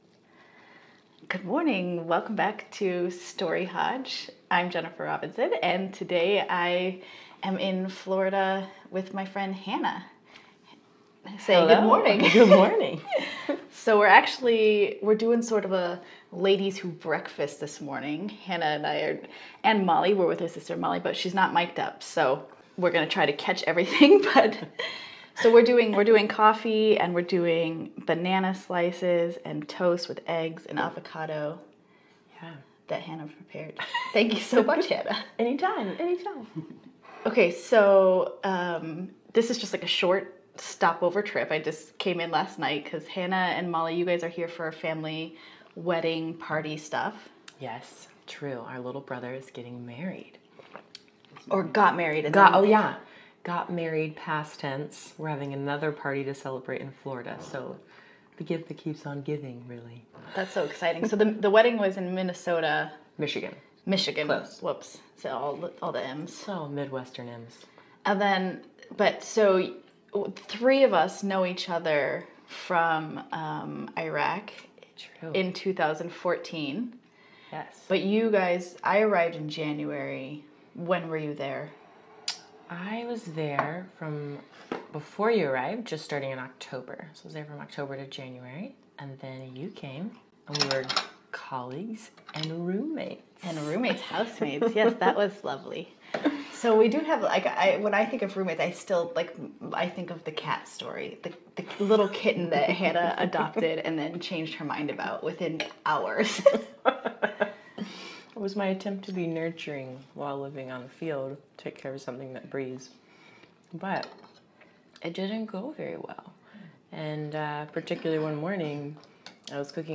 Over a noisy breakfast in Florida, my friend and I talk about our early days in Iraq: that time we came across an anti-personnel mine while hiking and our attempt to introduce Persian Zumba to the ladies of Arbat.